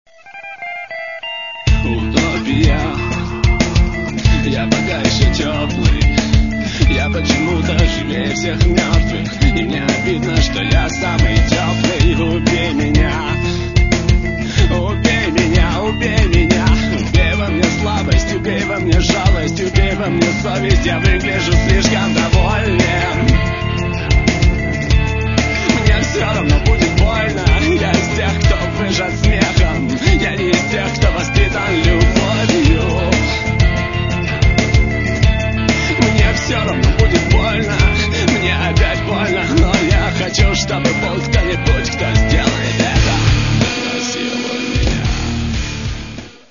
Каталог -> Рок та альтернатива -> Енергійний рок